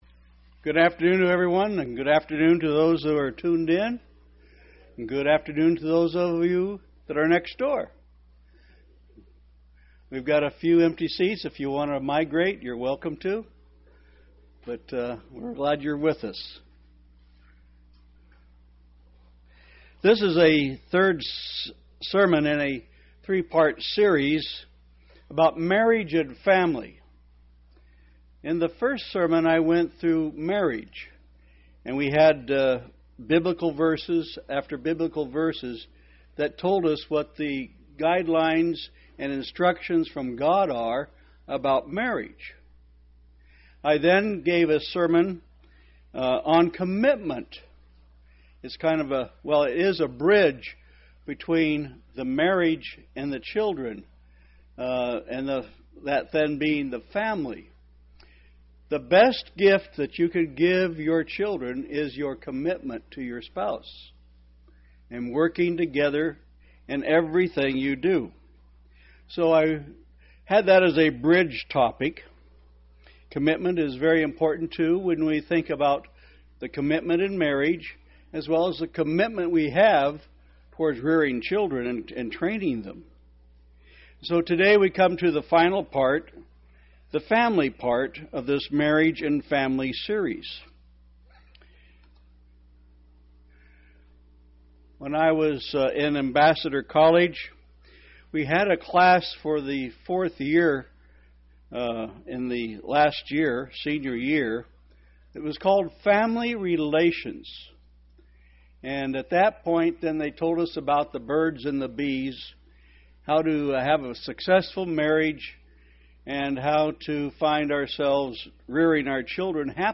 Given in Tampa, FL St. Petersburg, FL
UCG Sermon Studying the bible?